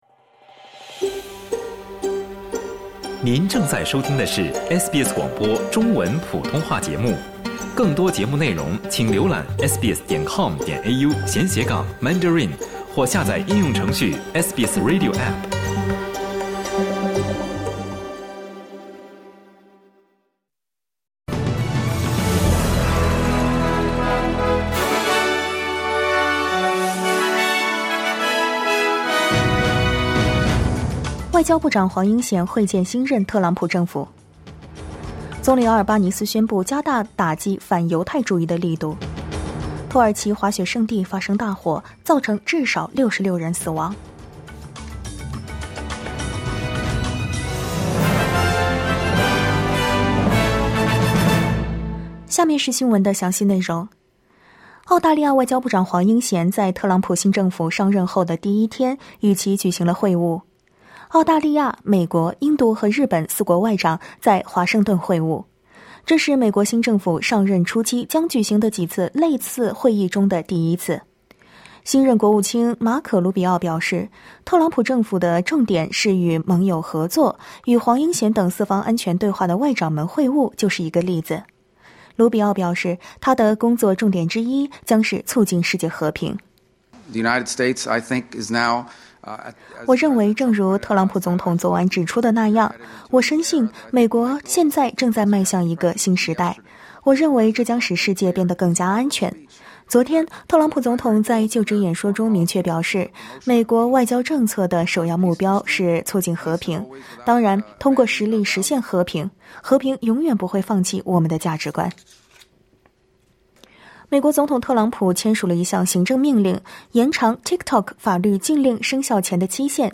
SBS早新闻（2025年1月22日）